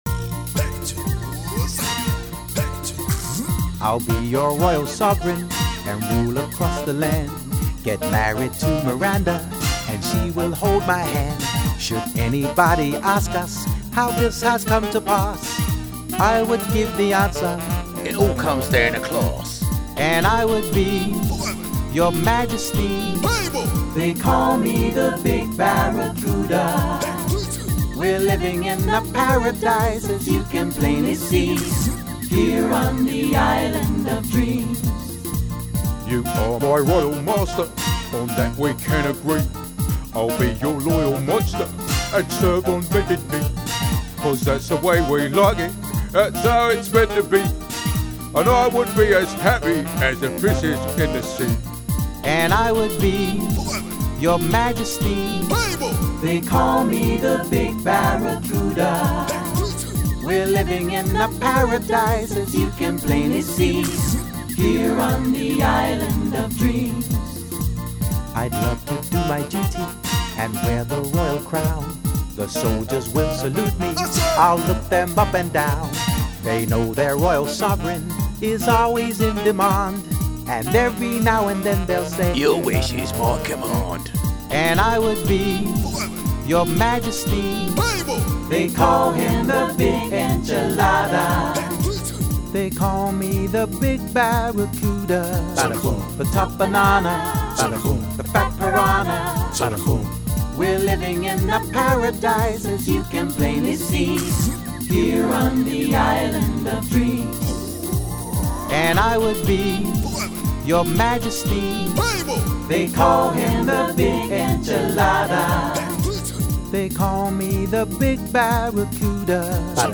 full vocal